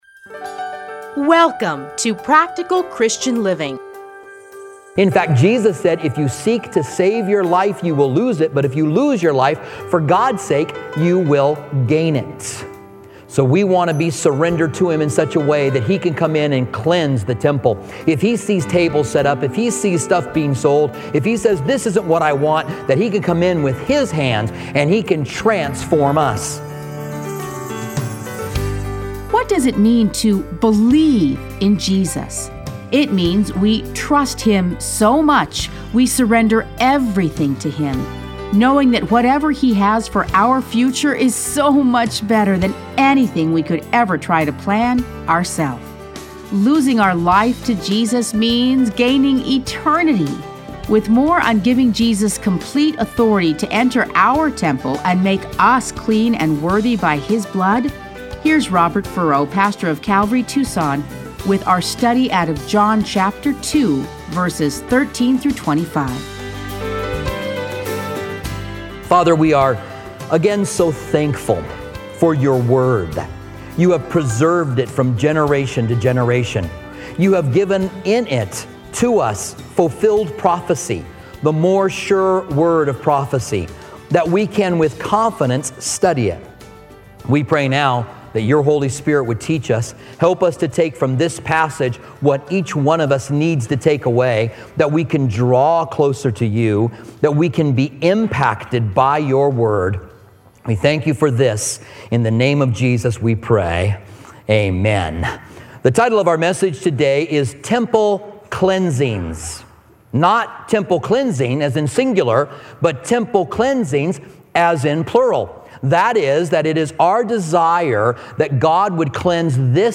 Listen to a teaching from John 2:13-25.